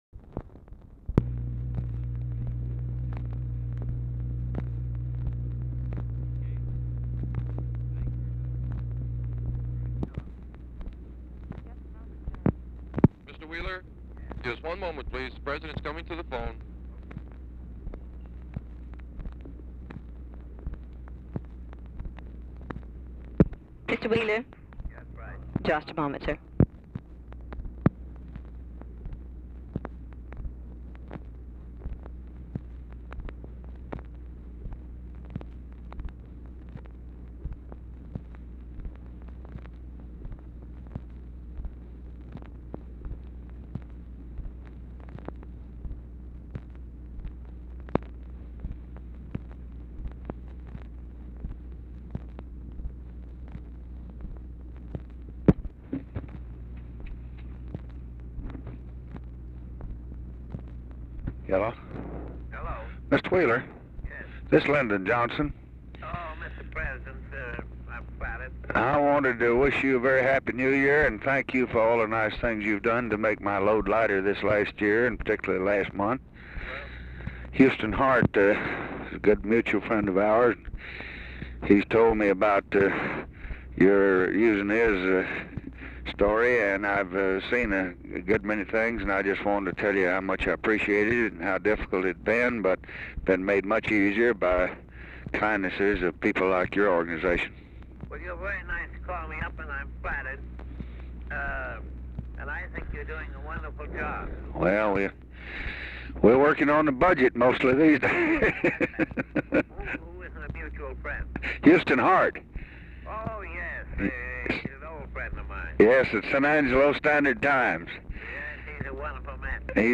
Telephone conversation
FAINT VOICES IN BACKGROUND AT BEGINNING
Format Dictation belt